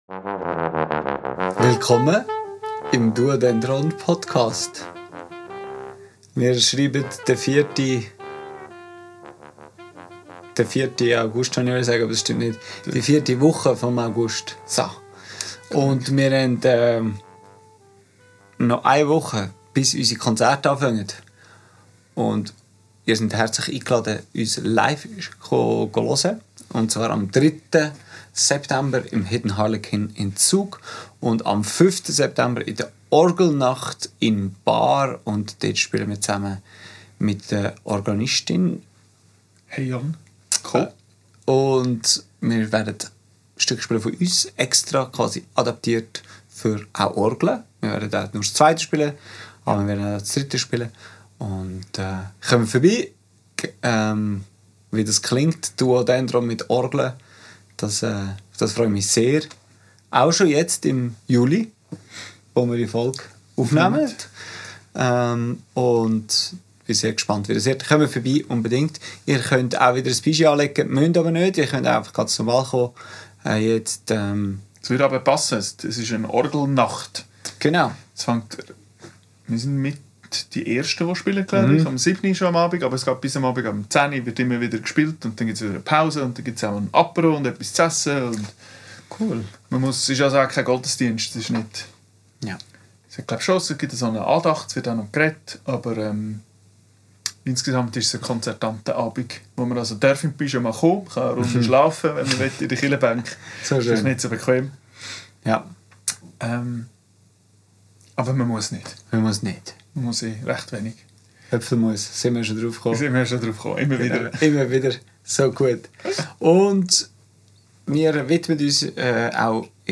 Aufgenommen am 14.07.2025 im Atelier